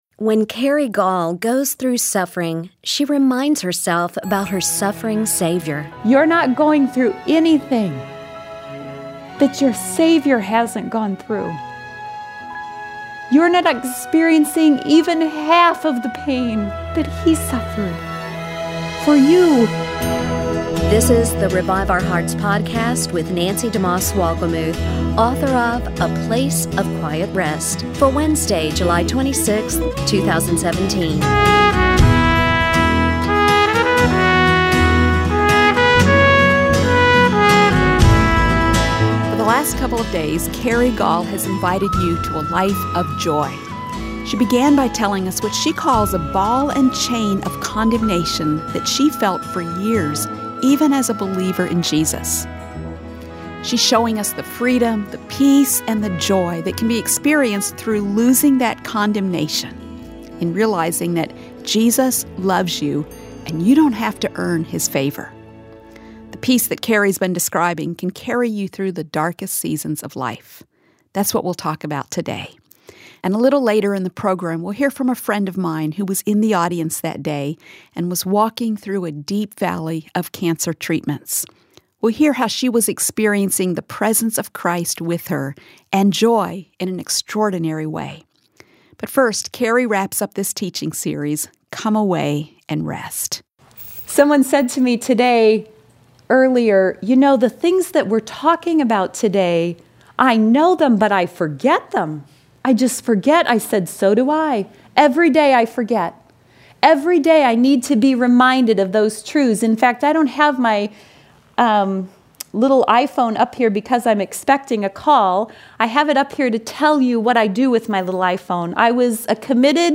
Did you know you can receive suffering as a gift? We’ll hear from two women who have undergone very difficult medical treatments.